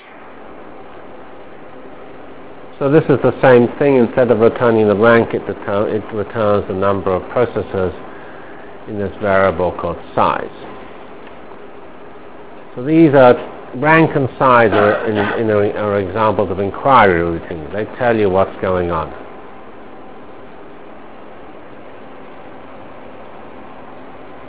Delivered Lectures of CPS615 Basic Simulation Track for Computational Science -- 31 October 96.